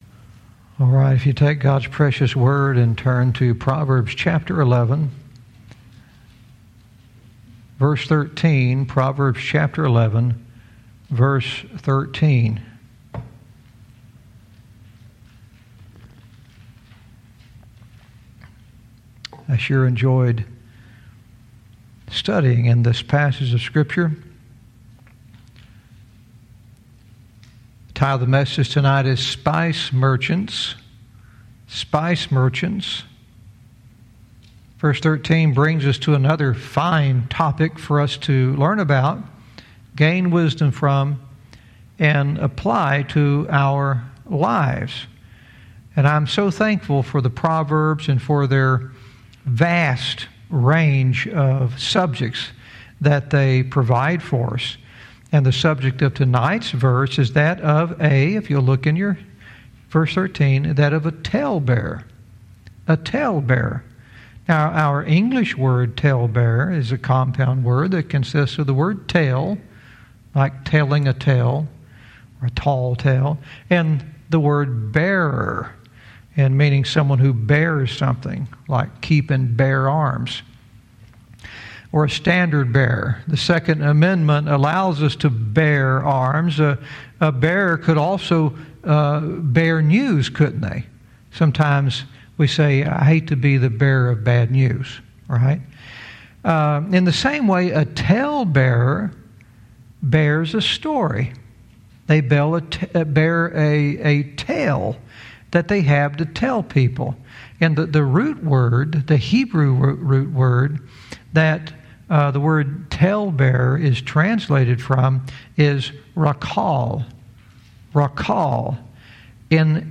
Verse by verse teaching - Proverbs 11:13 "Spice Merchants"